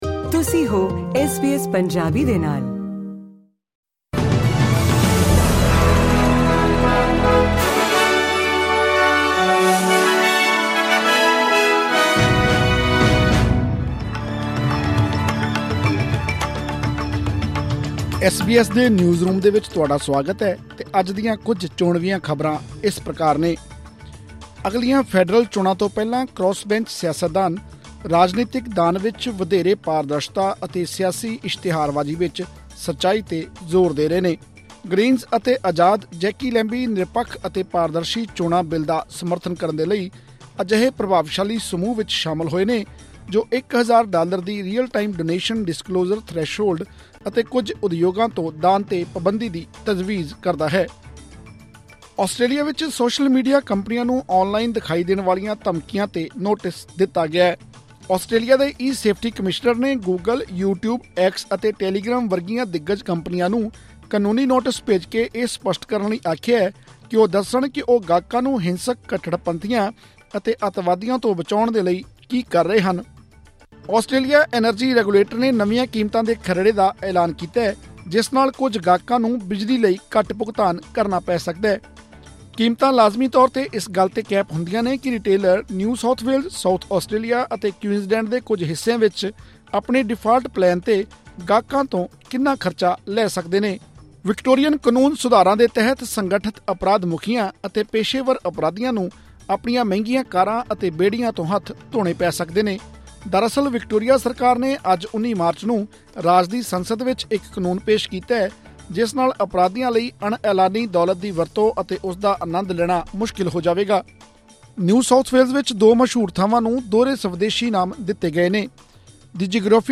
ਐਸ ਬੀ ਐਸ ਪੰਜਾਬੀ ਤੋਂ ਆਸਟ੍ਰੇਲੀਆ ਦੀਆਂ ਮੁੱਖ ਖ਼ਬਰਾਂ: 19 ਮਾਰਚ, 2024